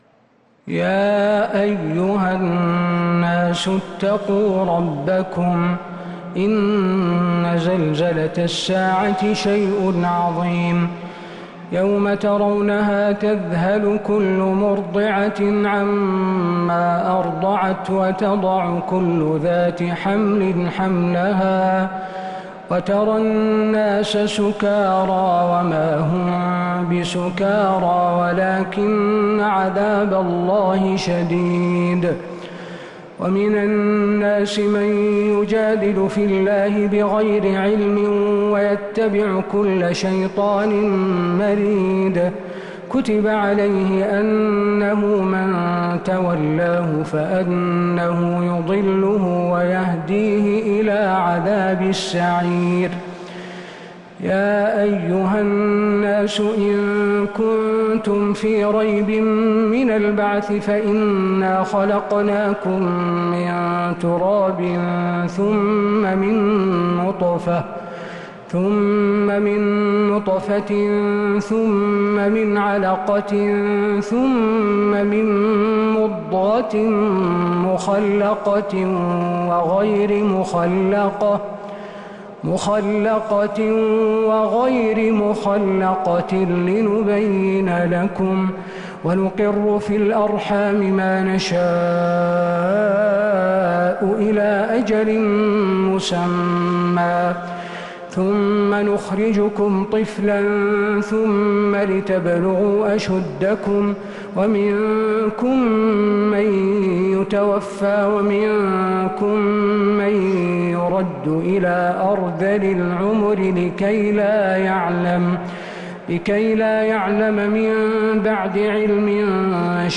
من الحرم النبوي